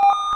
menu-select.ogg